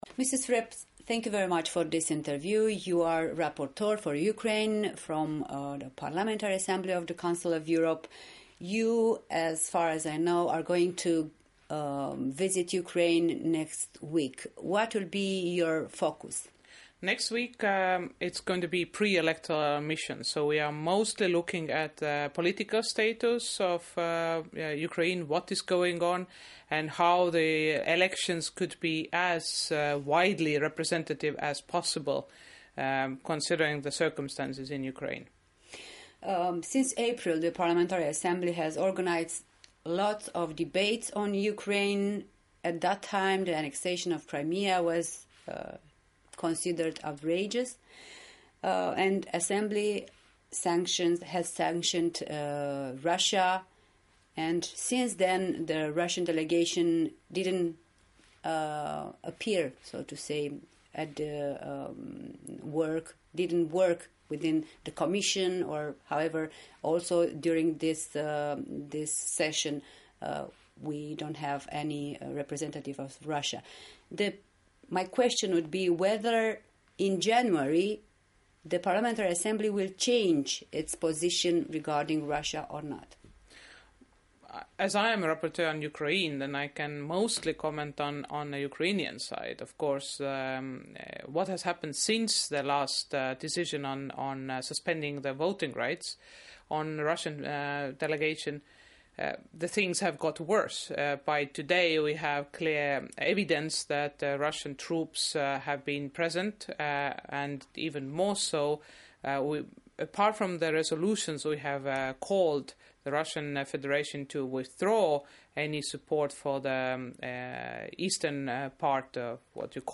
În direct de la Strasbourg
în dialog cu deputata estonă Mailis Reps